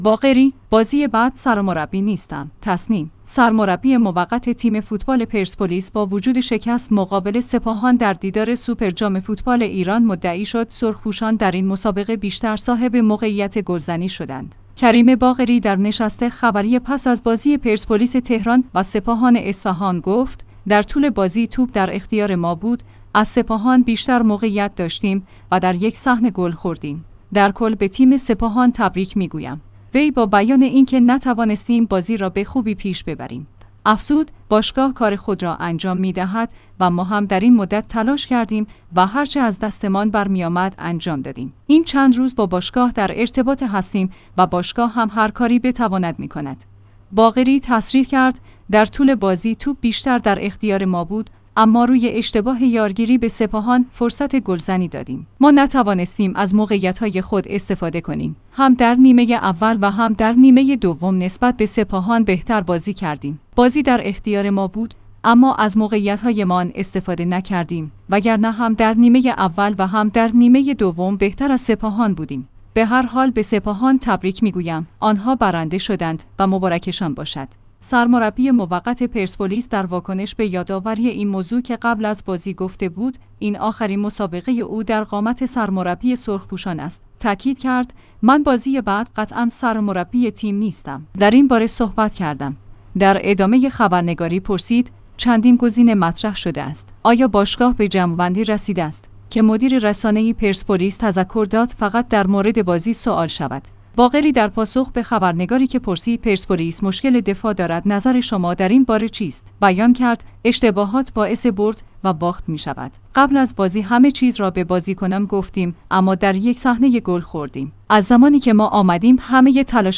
کریم باقری در نشست خبری پس از بازی پرسپولیس تهران و سپاهان اصفهان گفت: در طول بازی توپ در اختیار ما بود، از سپاهان بیشتر موقعیت داشتیم و در یک صح